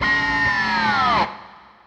guitarFX.wav